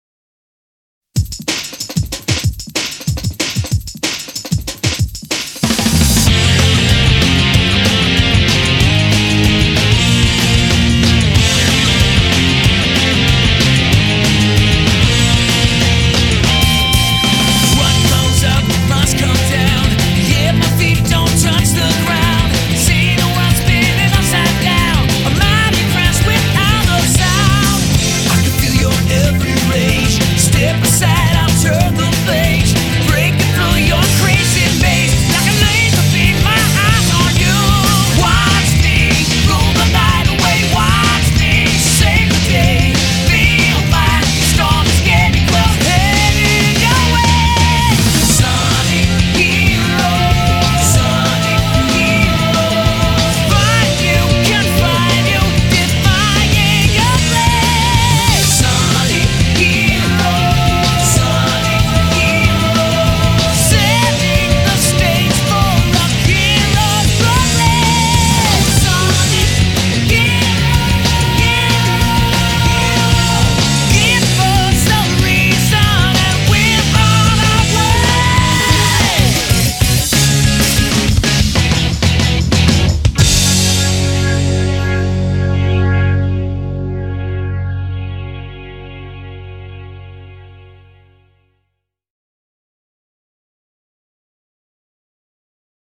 BPM188
Audio QualityCut From Video